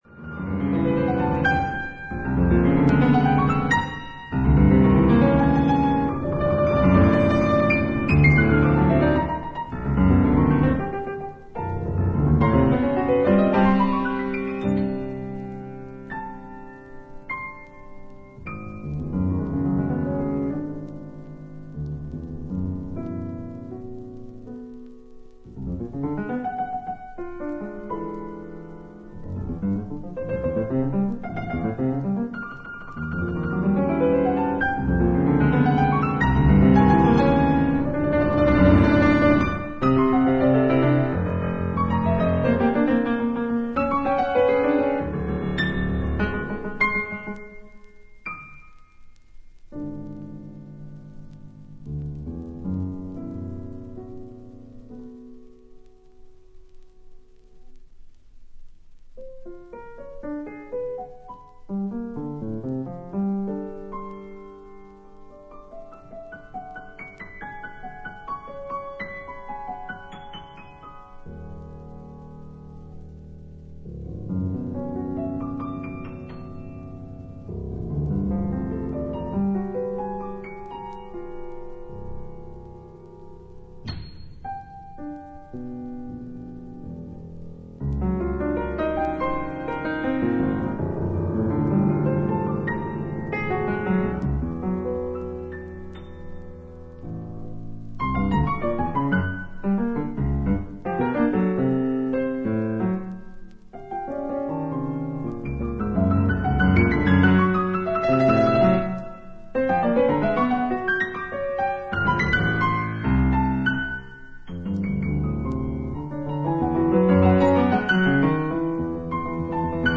内省的でありながら、フェロー独特の穏やかな響きが感じられるピアノ独奏曲が計4曲収録。